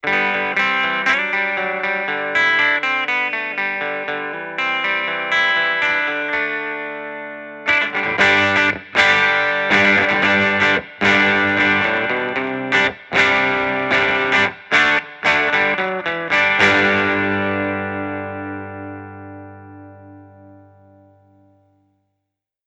Audio Technica AT-4050 - gitara akustyczna